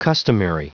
Prononciation du mot customary en anglais (fichier audio)
Prononciation du mot : customary